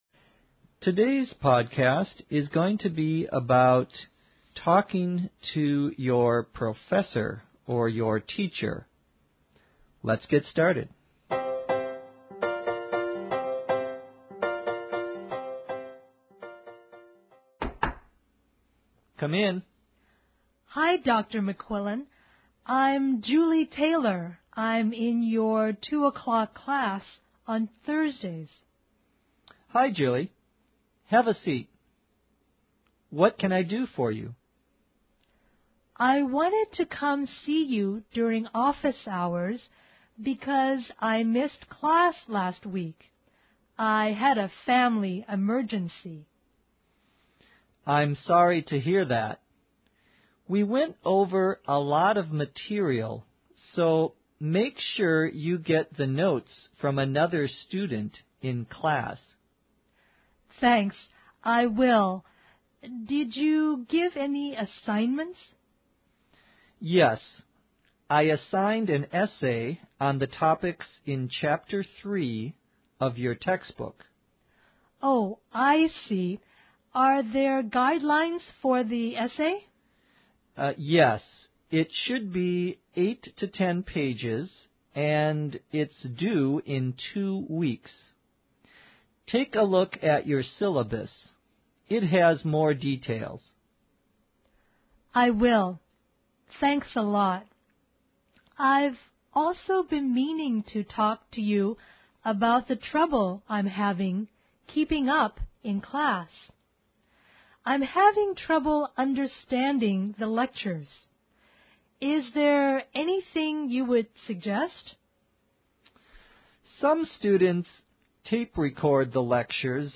[A knock on the door.]